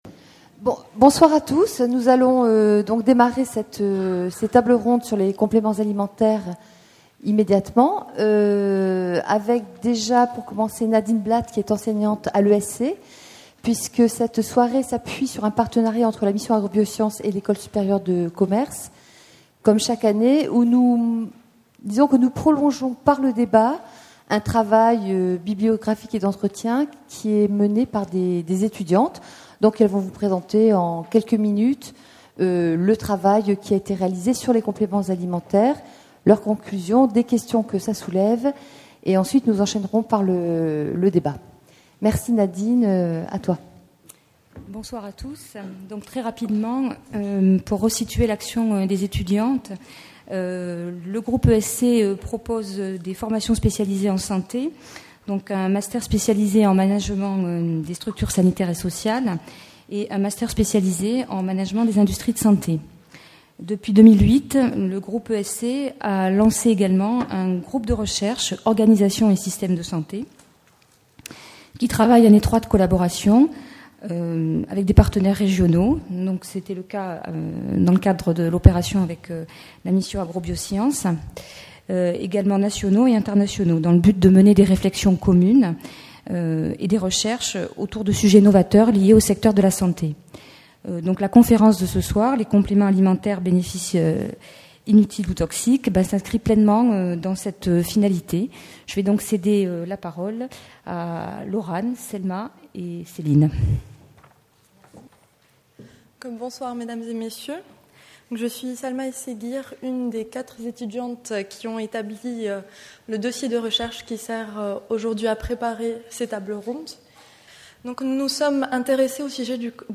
Conférence-débat co-organisée par la Mission Agrobiosciences et le Groupe ESC Toulouse9 décembre 2010, hôtel de région Midi-PyrénéesAprès la relation entre l’alimentation et le cancer, puis les cosmétiques bio, cette troisième collaboration entre la Mission Agrobiosciences et l’Ecole supérieure de commerce de Toulouse porte sur les compléments alimentaires, ces fameux suppléments dont les consommateurs attende